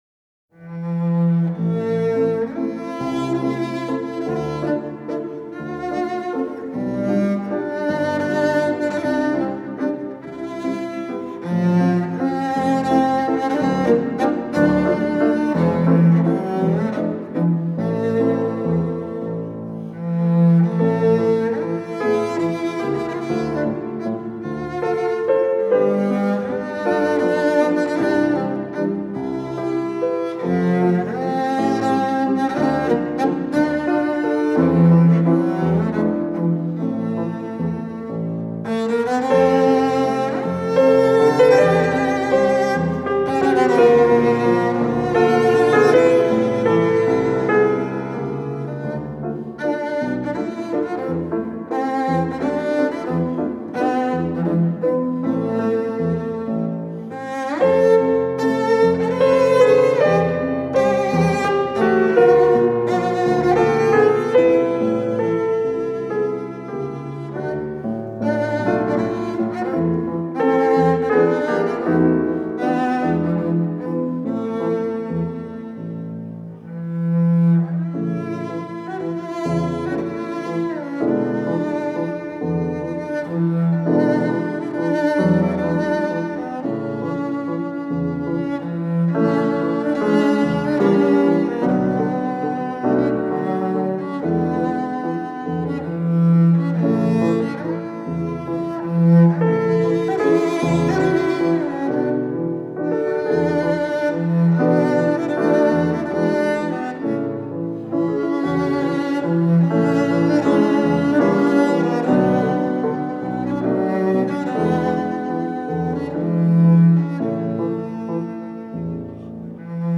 06-Liebesleid-for-Double-Bass-and-Piano.m4a